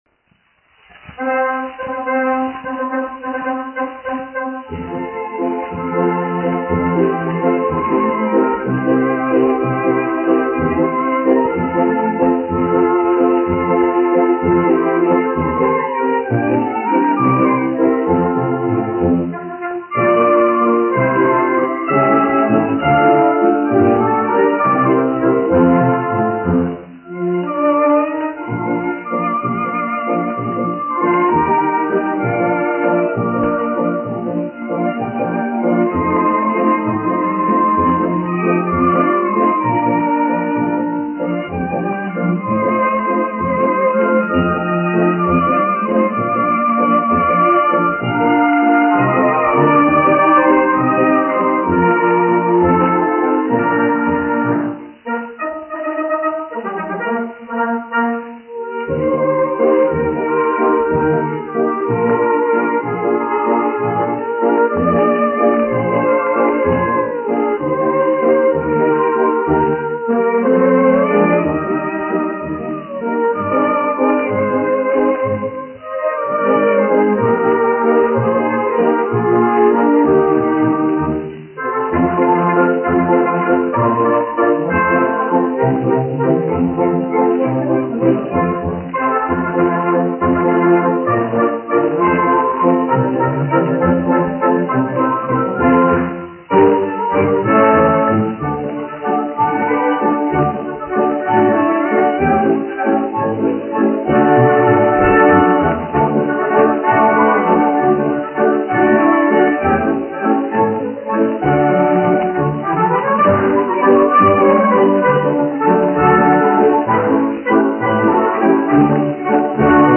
1 skpl. : analogs, 78 apgr/min, mono ; 25 cm
Populārā instrumentālā mūzika
Skaņuplate